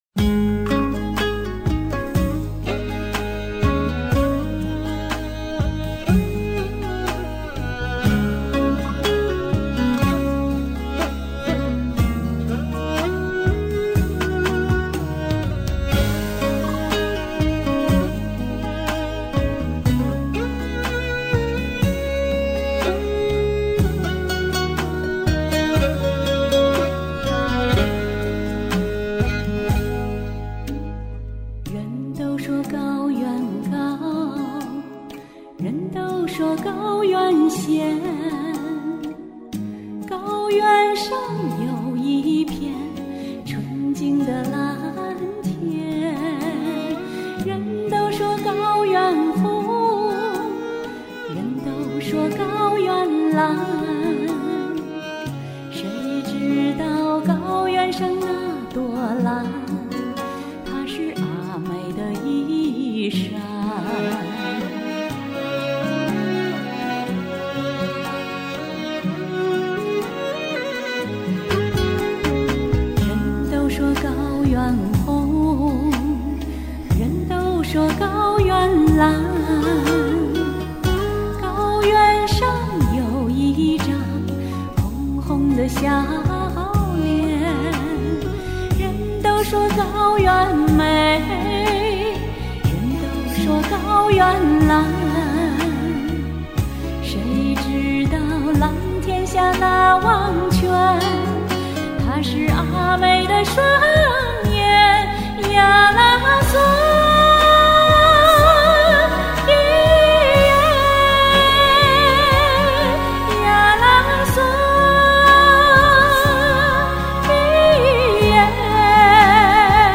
俺也来赶个高原的时髦吧。